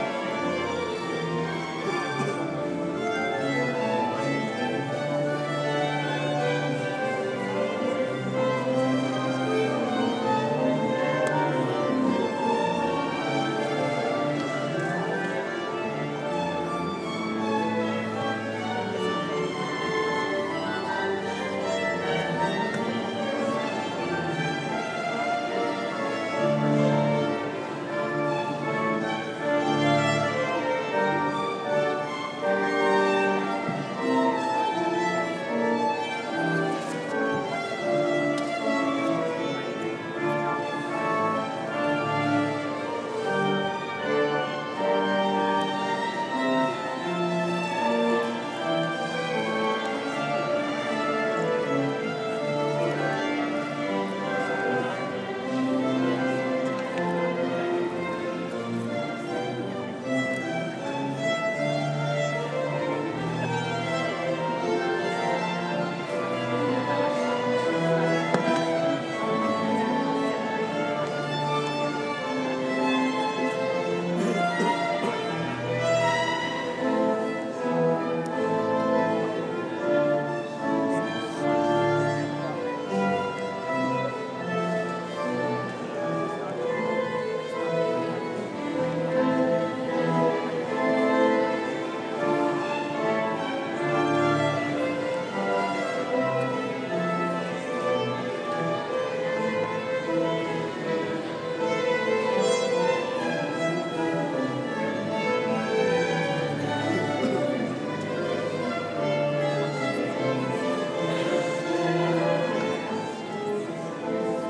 Concerto in D Minor, JS Bach, prelude to Mass for 23rd Sunday Ordinary Time, Basilica of Sacred Heart, university of Notre Dame